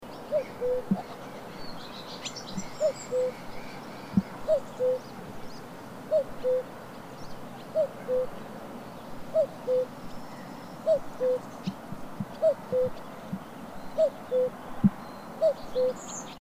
Со звуками леса очень естесственно смотрится!
Сначала хотел музыку подключить, а потом решил, что лучше лесные звуки.